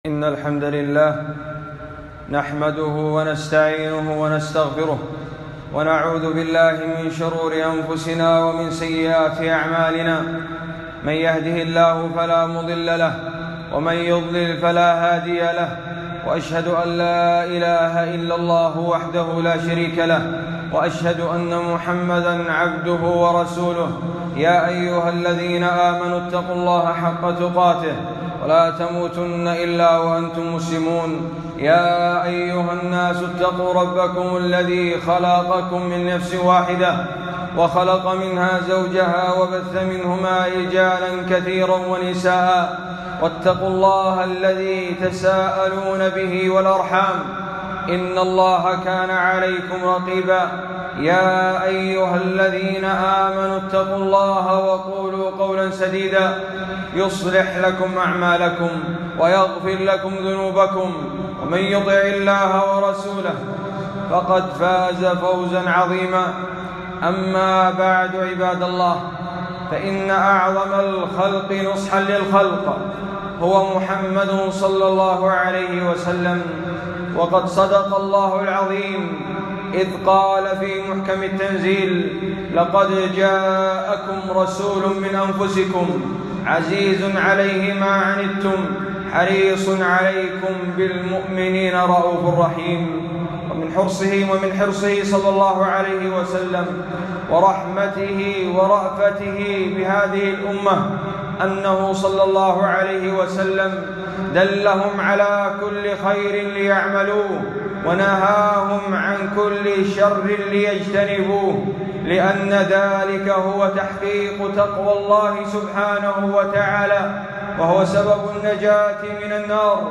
خطبة - من وصايا النبي لمعاذ